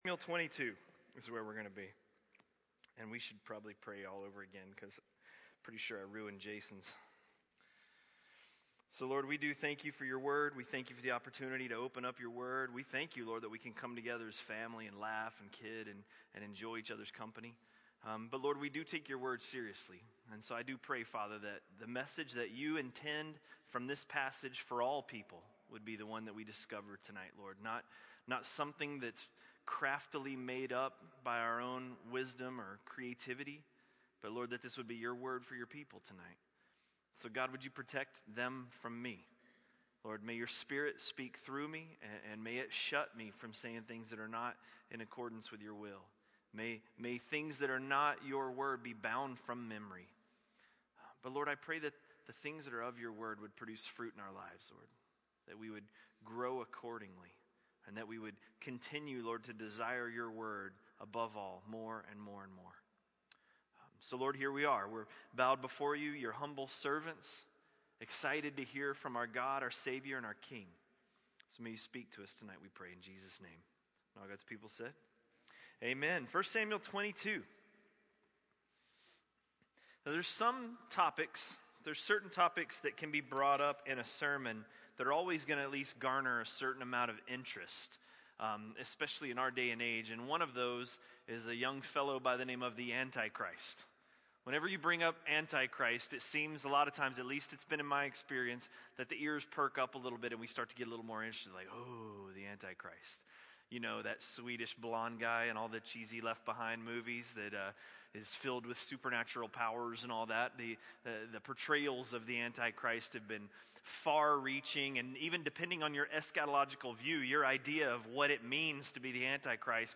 A message from the series "1 Samuel." 1 Samuel 22